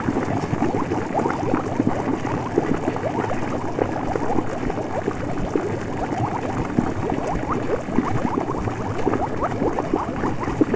potion_bubbles_brewing_loop_04.wav